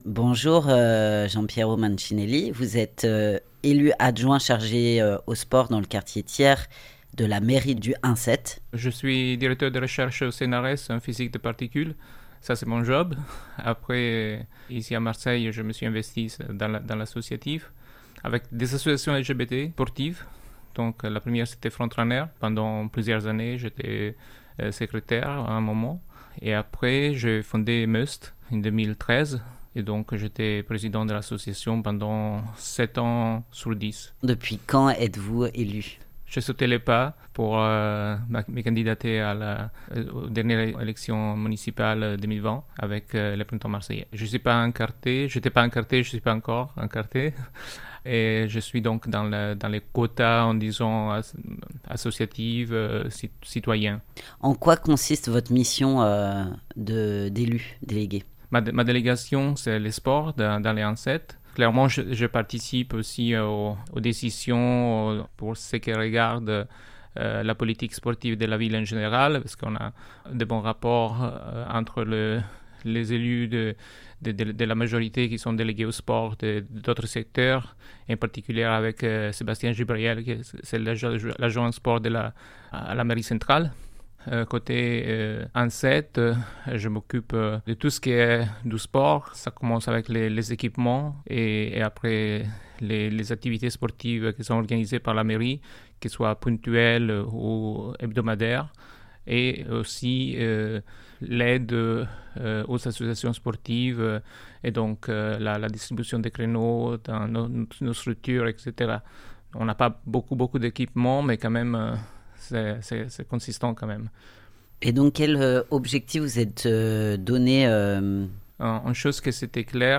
Du sport sans prise de tête Vendredi 30 Juin 2023 Rencontre avec Giampiero Mancinelli, élu délégué au sport de la mairie du 1/7 à Marseille. Giampiero Mancinelli.mp3 (11.23 Mo) Présentation des équipements et différentes actions sportives menées à la mairie du 1/7